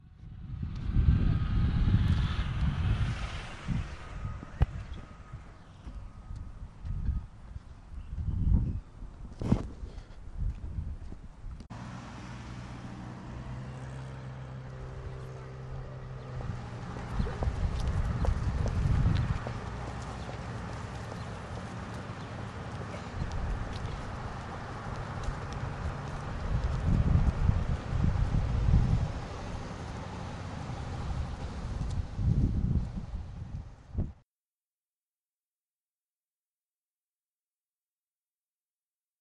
描述：人行横道，在日本京都伏见稻荷附近录制。用Zoom H1录制的。
标签： 行人 路口
声道立体声